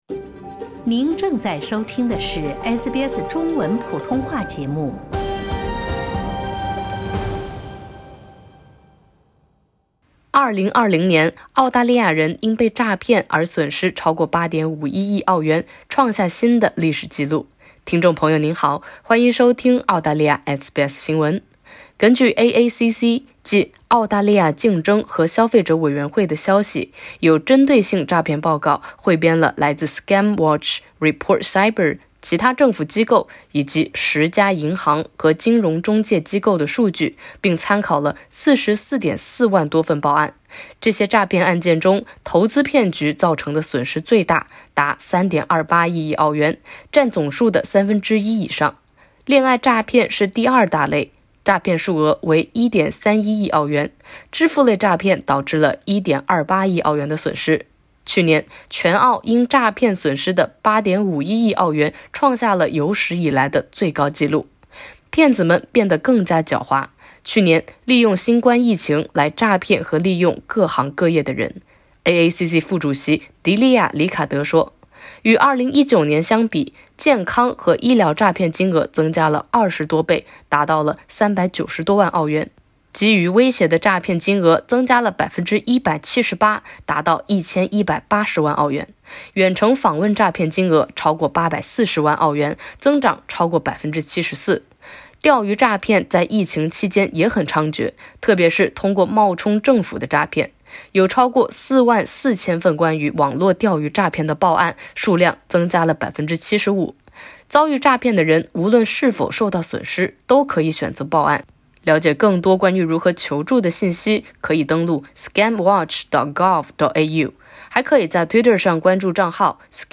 疫情之下澳洲人遭遇的最大骗局是投资骗局，其次为恋爱诈骗。（点击上图收听报道）